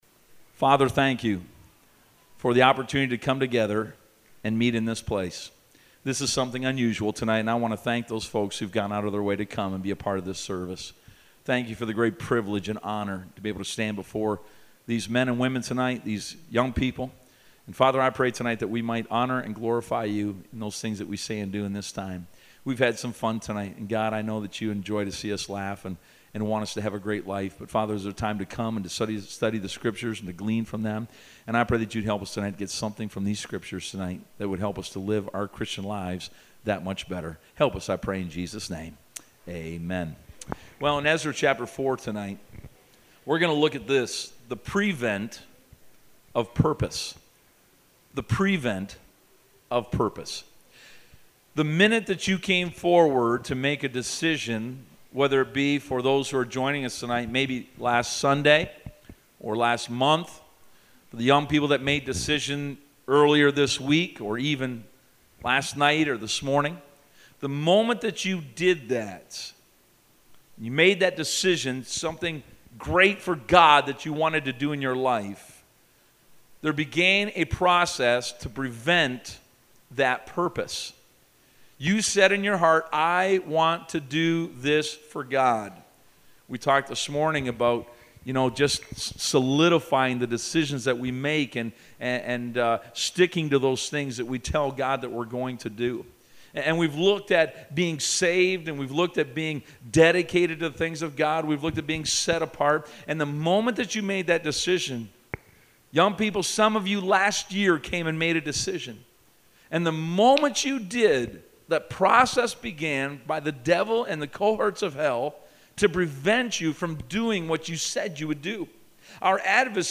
Service Type: Teen Camp